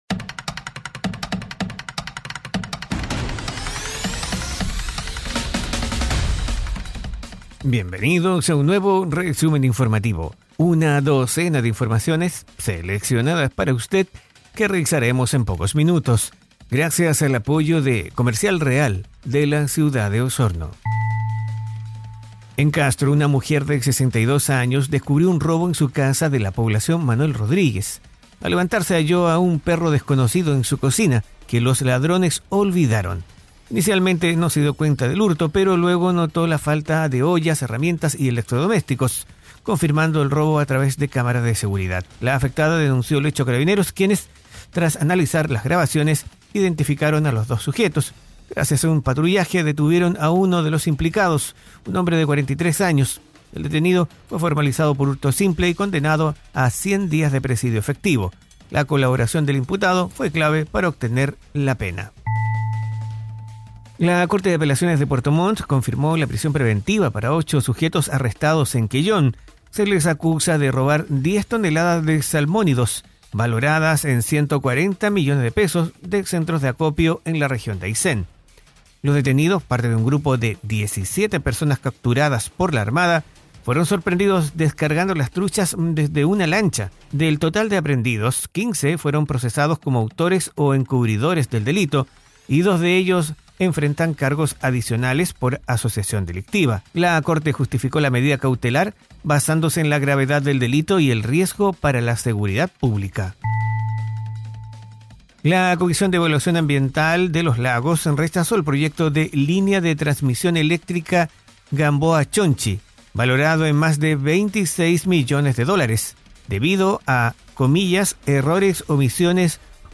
Resumen Informativo 🎙 Podcast 27 de agosto de 2025